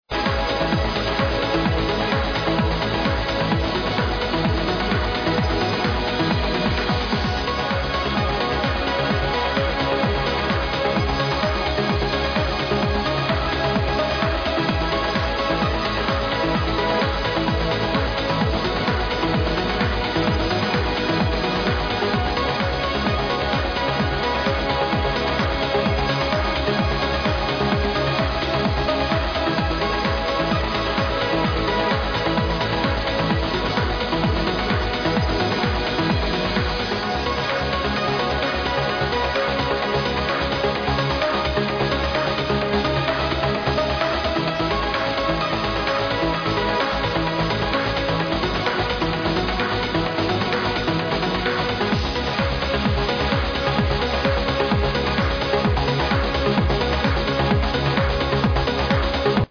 Chillin Trance (piano sound)
[played at minus 8 pitch]